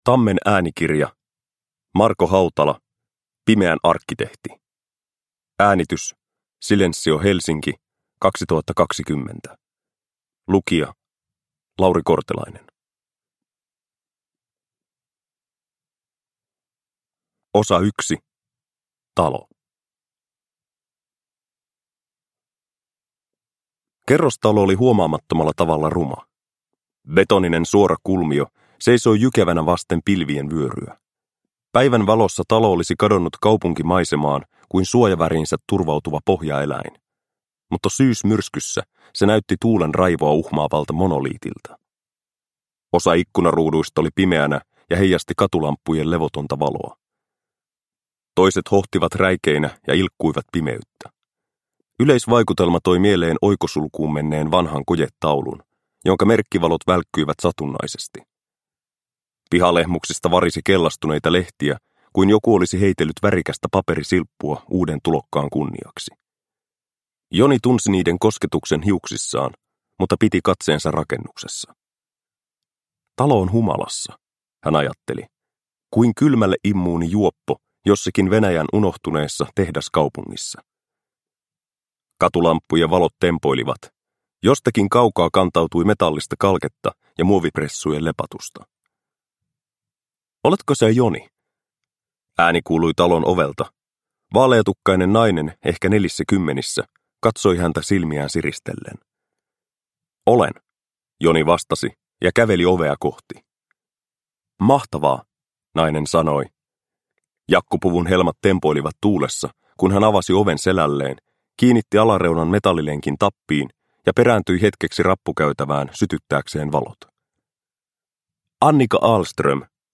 Pimeän arkkitehti – Ljudbok – Laddas ner